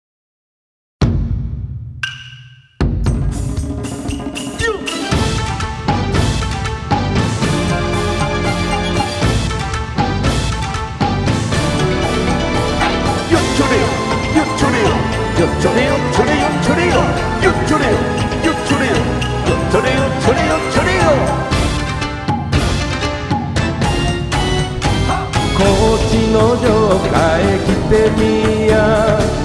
（歌あり）